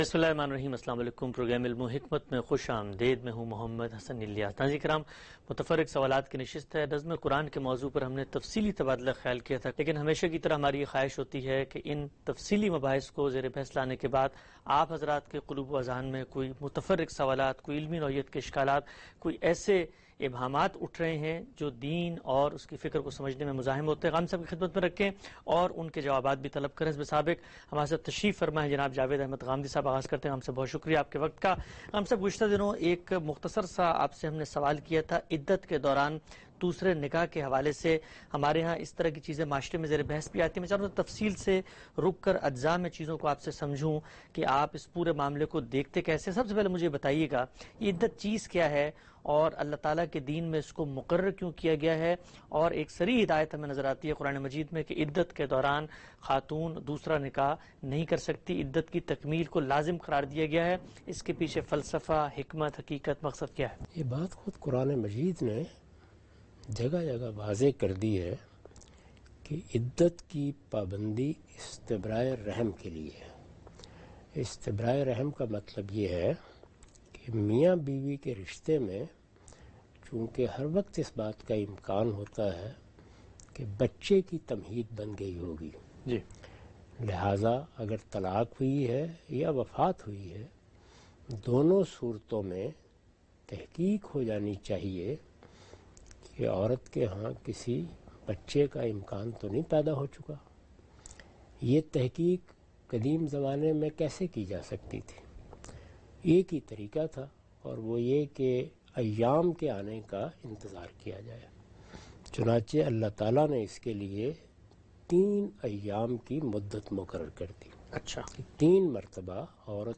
In this program Javed Ahmad Ghamidi answers the questions about "Iddah Rulings".